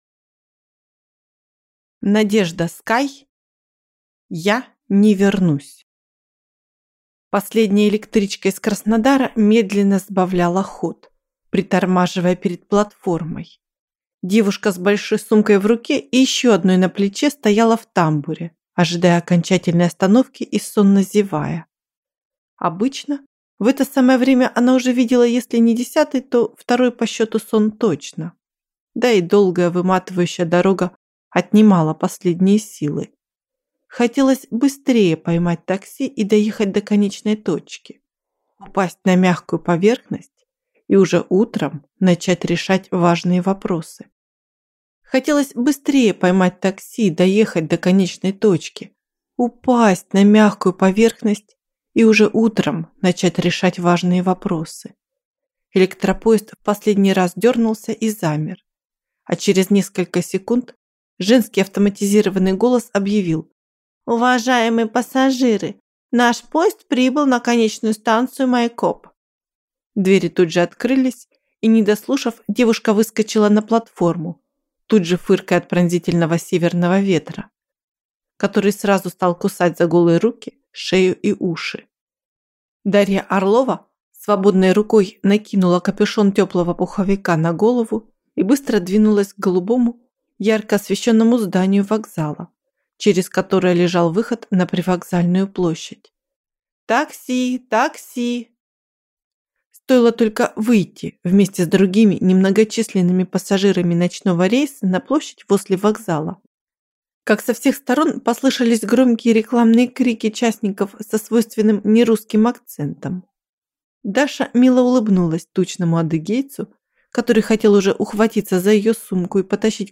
Аудиокнига Я (не)вернусь | Библиотека аудиокниг